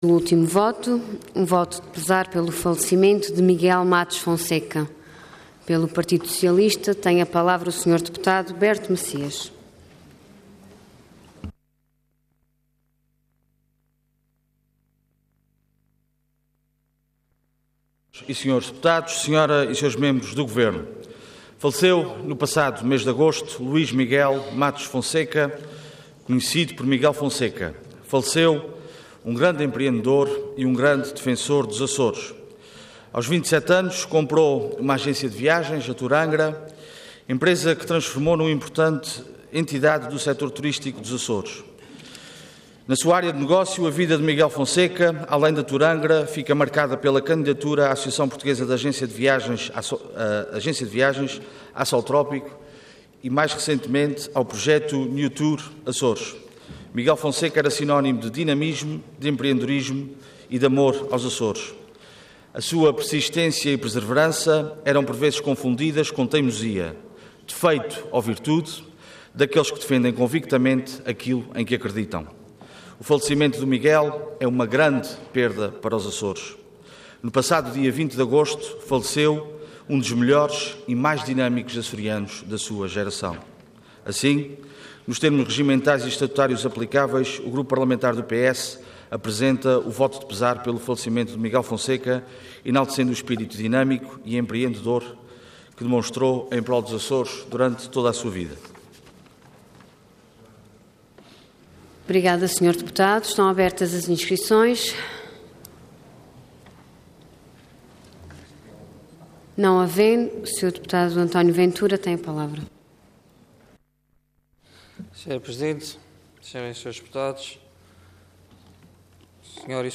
Website da Assembleia Legislativa da Região Autónoma dos Açores
Intervenção Voto de Pesar Orador Berto Messias Cargo Deputado Entidade PS